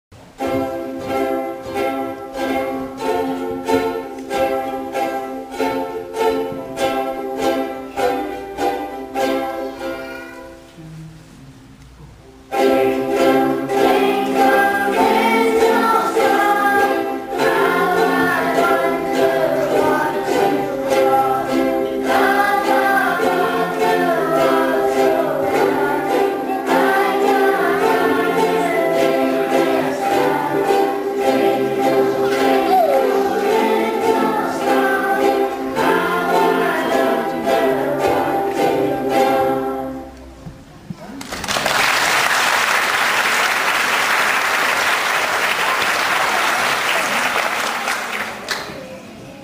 This recording is taken from our school Christmas concert where Primary 6 played ukulele!